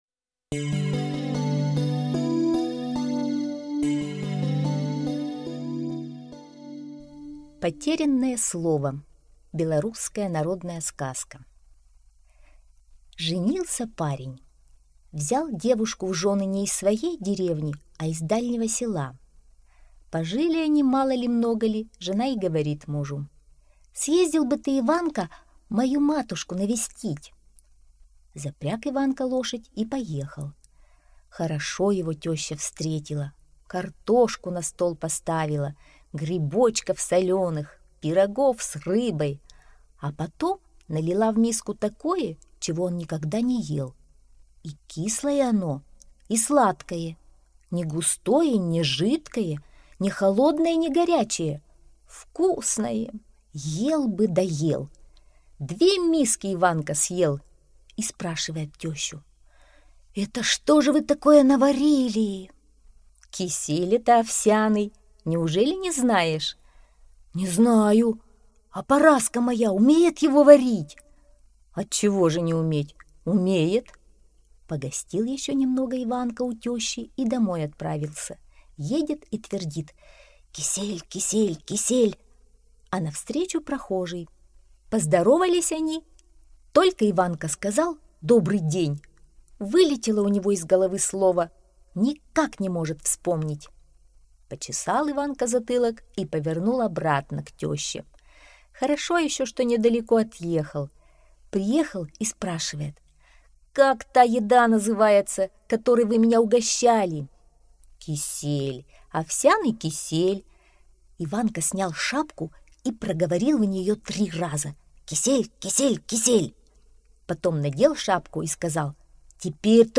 Потерянное слово - белорусская аудиосказка - слушать